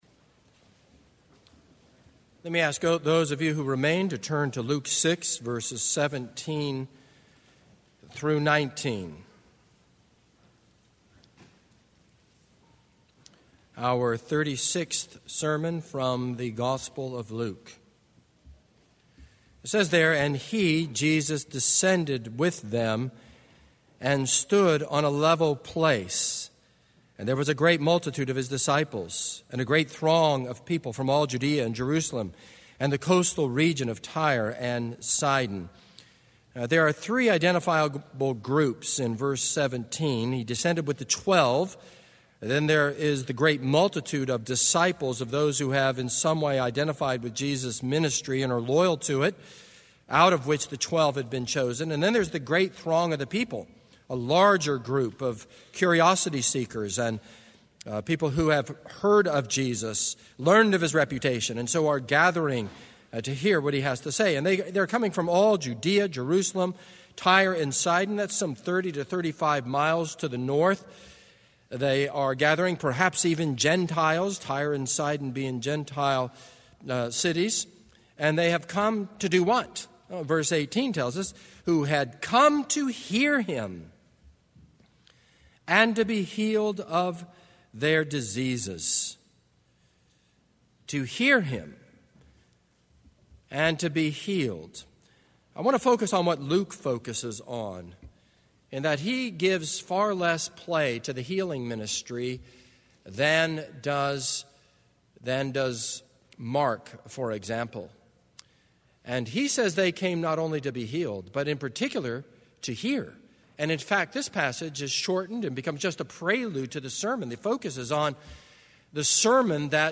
This is a sermon on Luke 6.17-19.